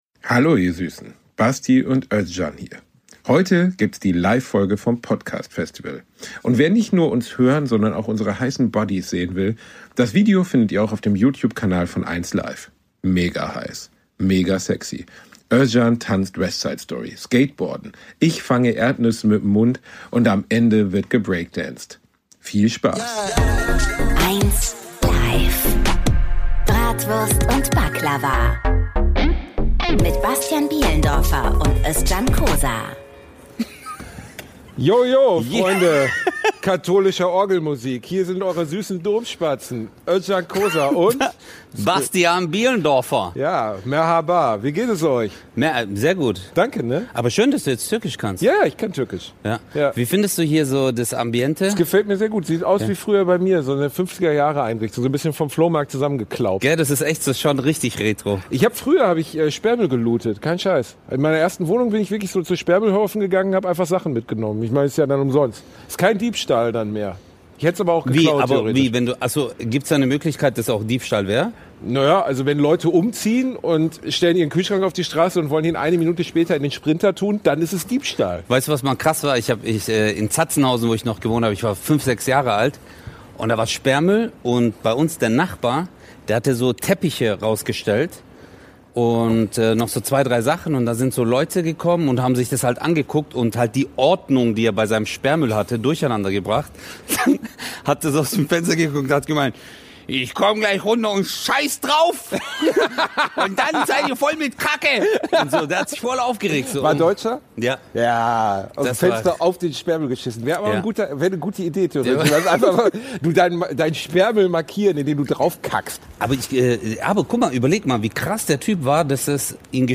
#88 Wayne interessierts - live beim 1LIVE Podcastfestival ~ Bratwurst und Baklava - mit Özcan Cosar und Bastian Bielendorfer Podcast